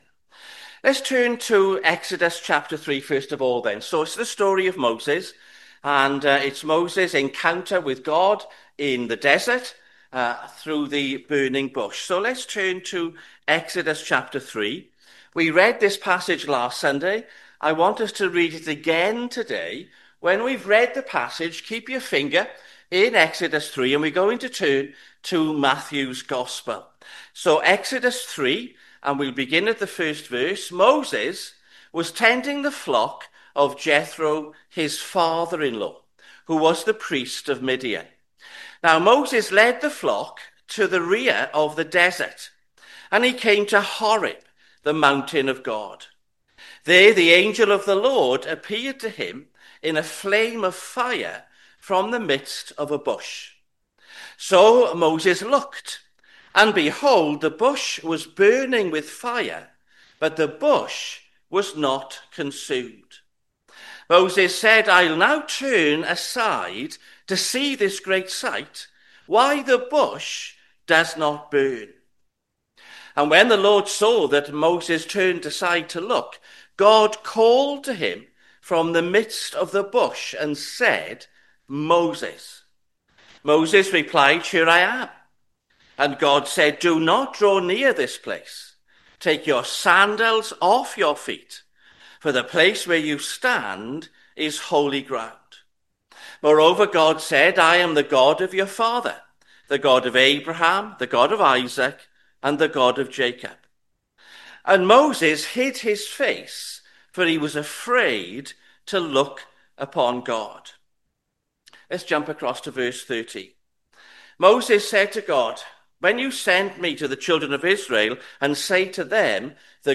sermon-2025-f-8th-june-am.mp3